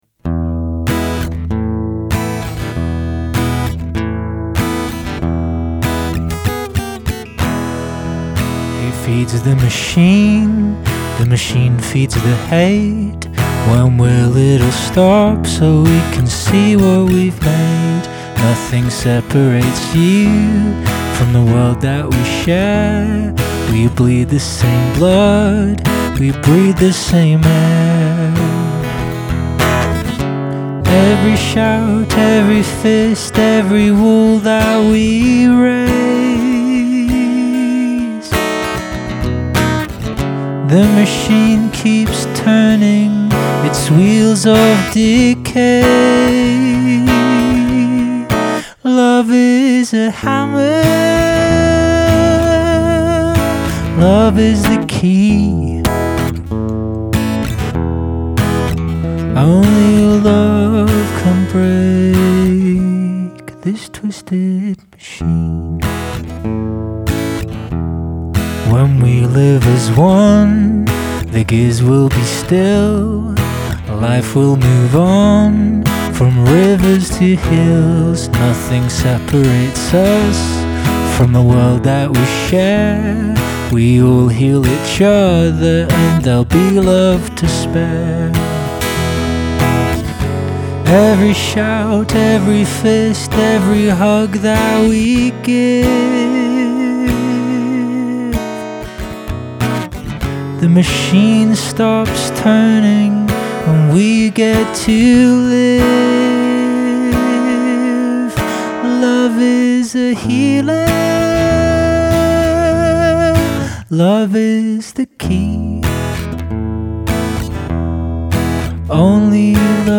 Written by Service children at the East Wales Armed Forces Festival (December 2025).